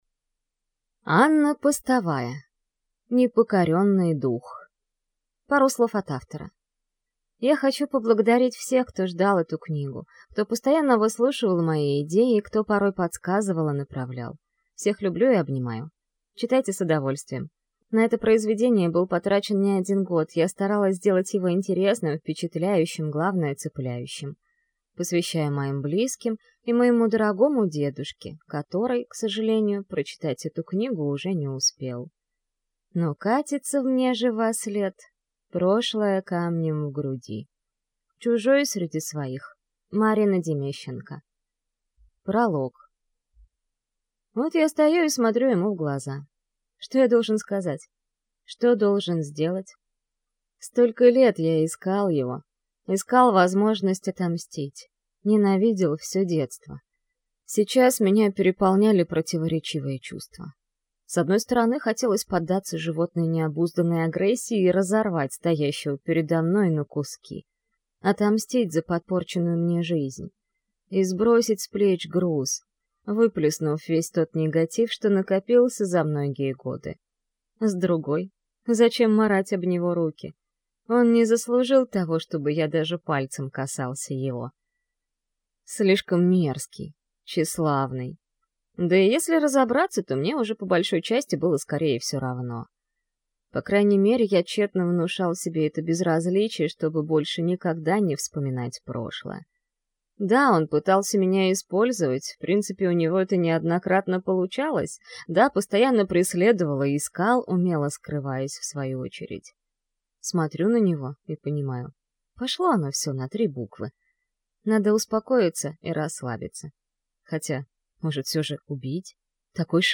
Аудиокнига Непокоренный дух | Библиотека аудиокниг